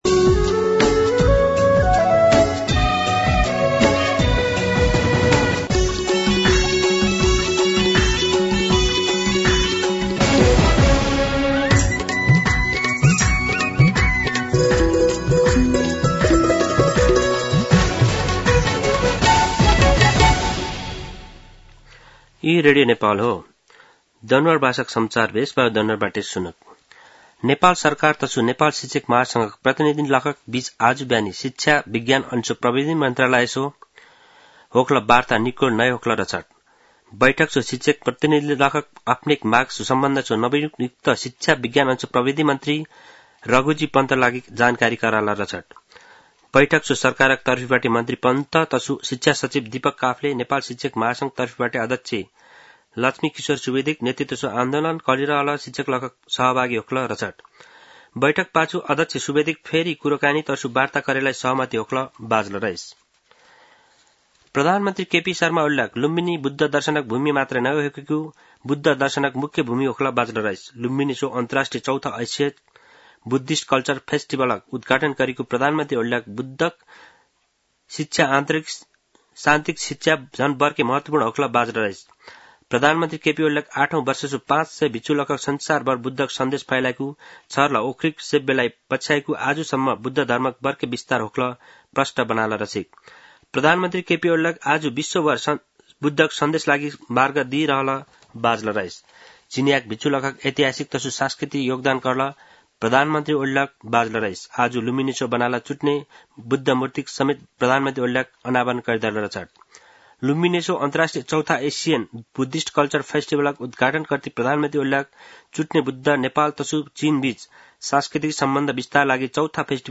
दनुवार भाषामा समाचार : १३ वैशाख , २०८२
Danuwar-News-13.mp3